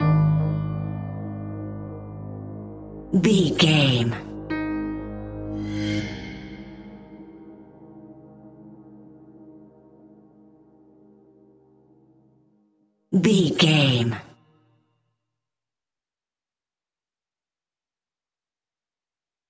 Aeolian/Minor
Slow
scary
ominous
haunting
eerie
melancholy
synthesiser
piano
cello
strings
horror music